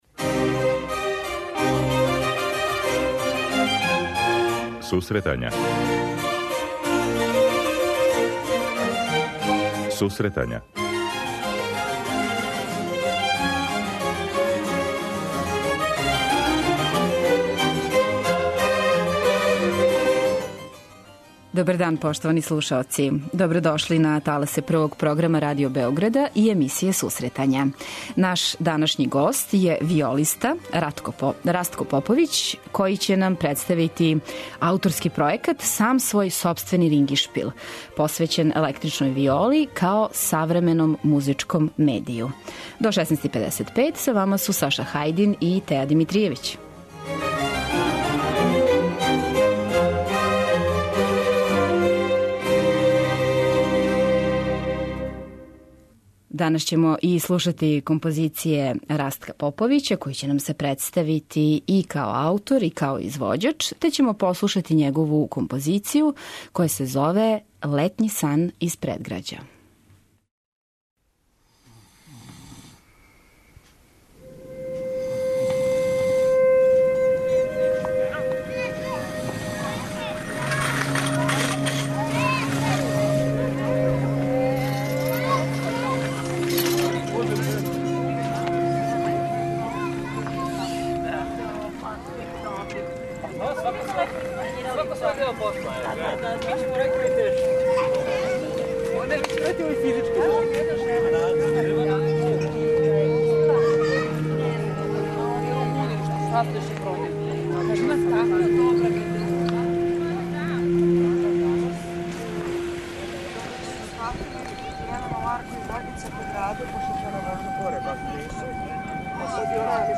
преузми : 26.21 MB Сусретања Autor: Музичка редакција Емисија за оне који воле уметничку музику.